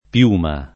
piuma [ p L2 ma ] s. f.